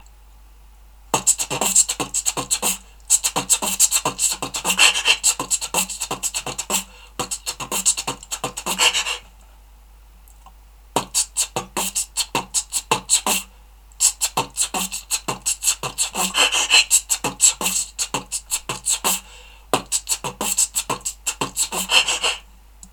аудио с битбоксом
b t t b pf t t b t t b t pf
t t b t pf t t b t t b t pf bri
t t b t pf t t b t t b t pf
b t t b pf t t b t t b t bri